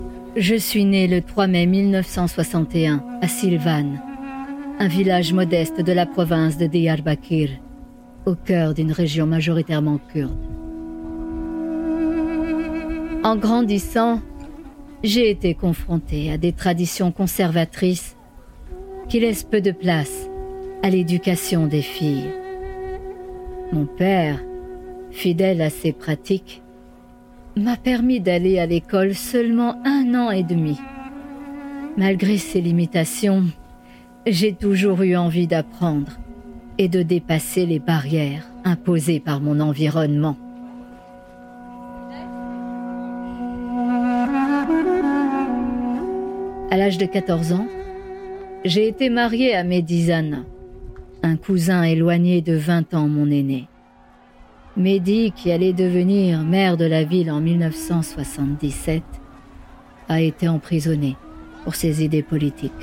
voix enfant garçon - Roméo - Podcast